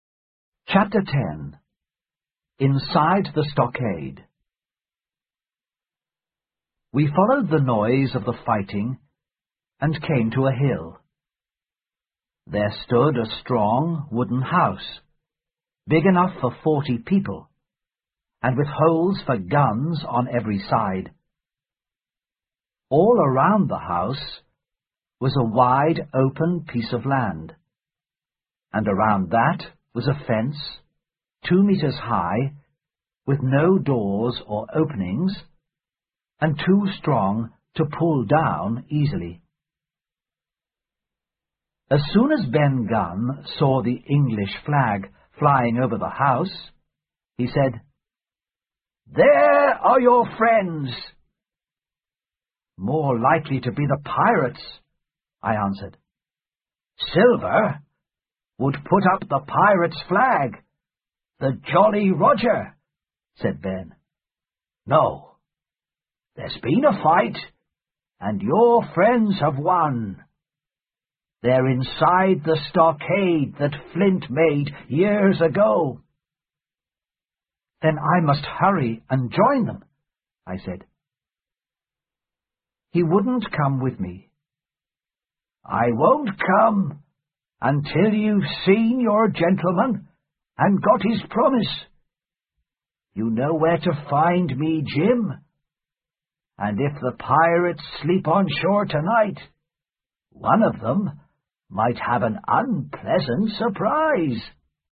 在线英语听力室《金银岛》第十章 在寨子里(1)的听力文件下载,《金银岛》中英双语有声读物附MP3下载